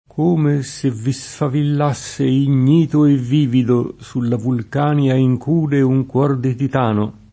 k1me SSe vvi Sfavill#SSe in’n’&to e vv&vido Su lla vulk#nLa ijk2de uj kU0r di tit#no] (D’Annunzio)